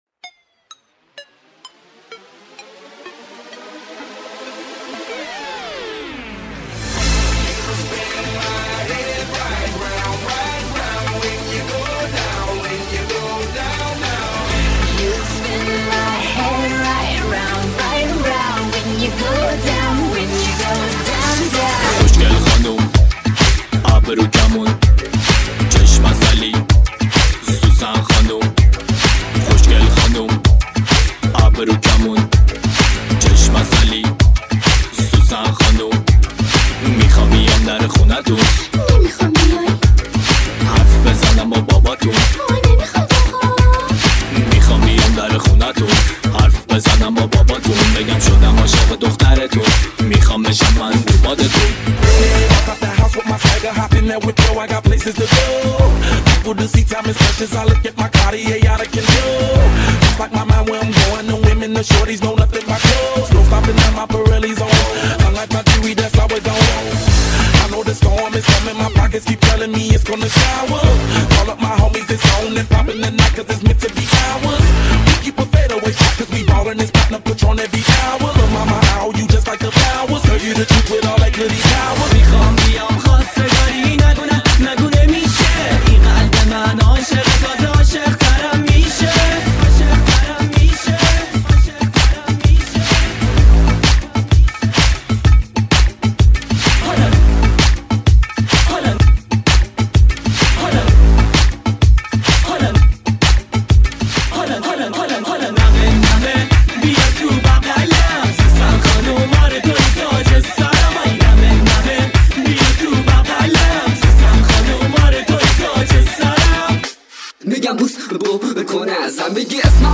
آهنگ شاد رقصی